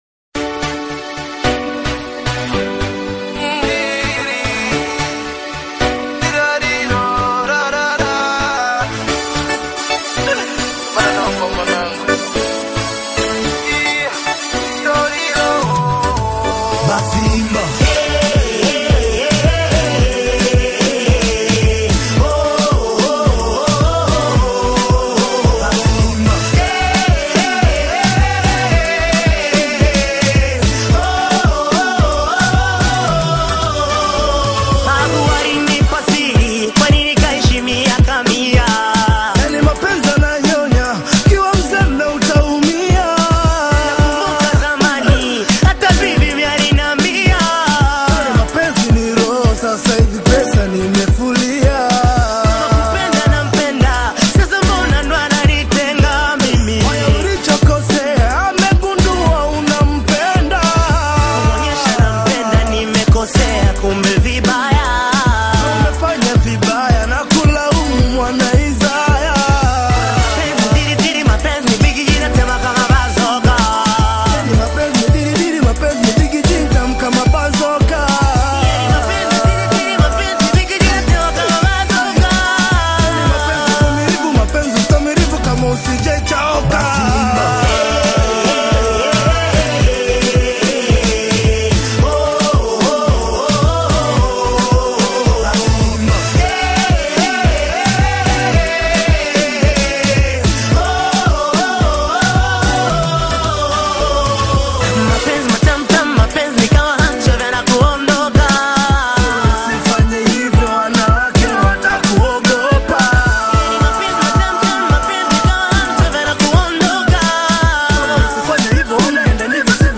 heartfelt musical creation
emotive delivery
The song resonates with deep emotions